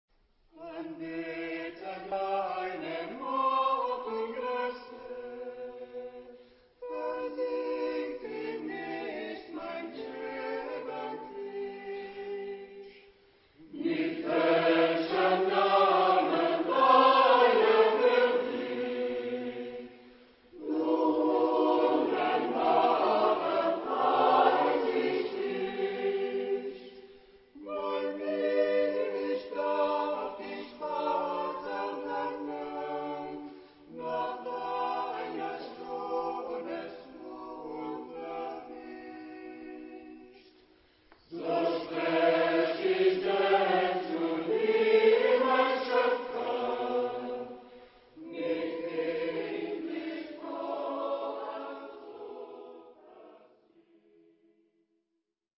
Genre-Style-Forme : Sacré ; Chœur ; Motet
Caractère de la pièce : ternaire ; modéré
Type de choeur : SATB  (4 voix mixtes )
Instrumentation : Orgue ou Piano  (1 partie(s) instrumentale(s))
Tonalité : mi mineur
interprété par Chorilla, Choeur de l'Ill et de la Largue